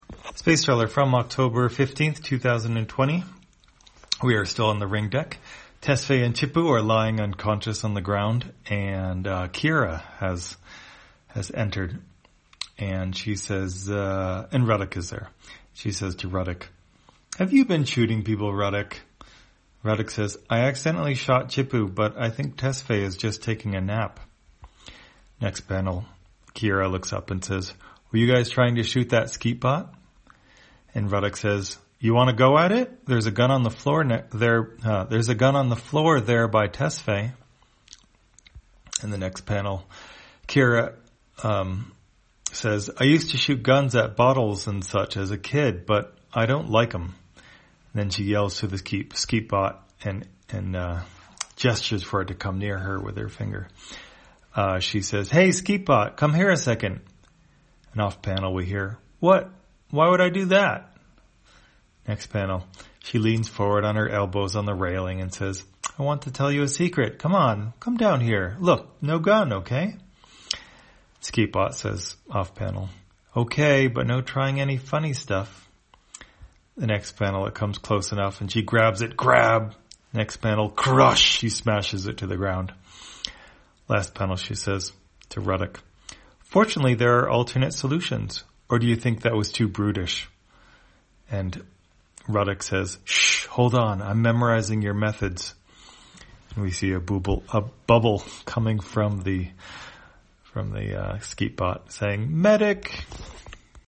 Spacetrawler, audio version For the blind or visually impaired, October 15, 2020.